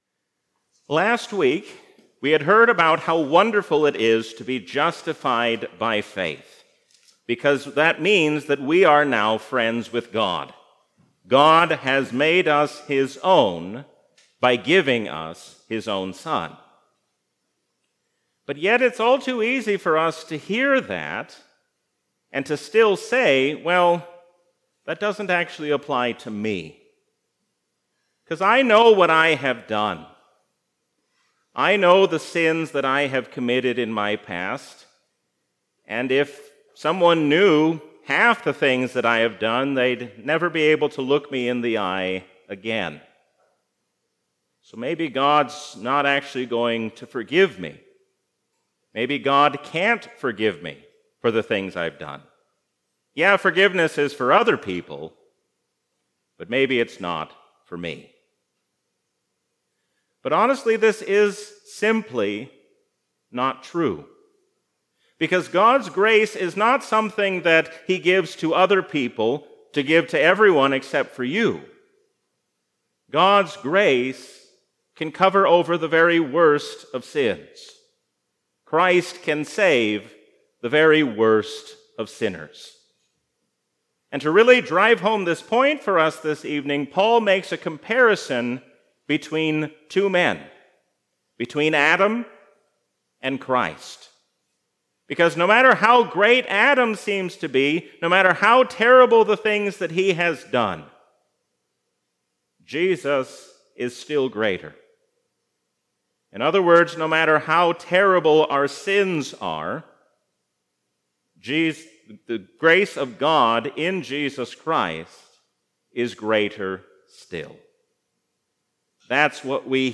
A sermon from the season "Lent 2023." Jesus teaches us what it means to forgive those who have hurt us, even as He hangs on the cross.